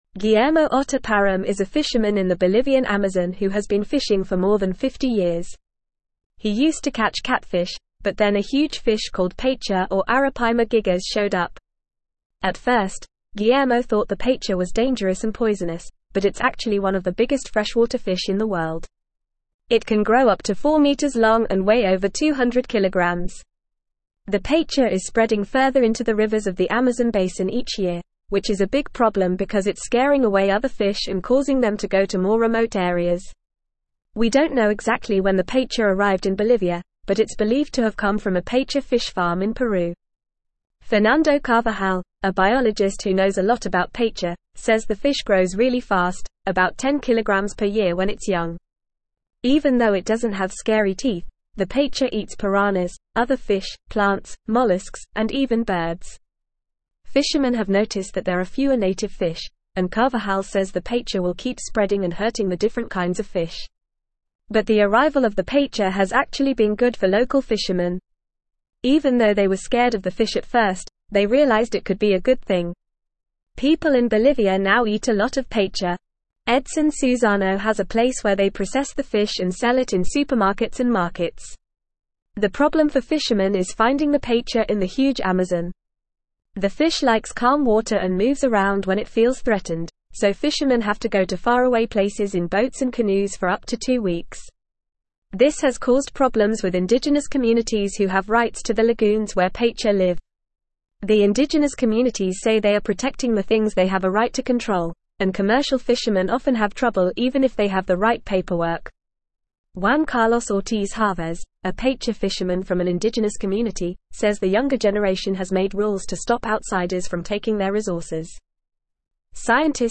Fast
English-Newsroom-Upper-Intermediate-FAST-Reading-Invasive-paiche-threatens-Bolivian-Amazon-fish-stocks.mp3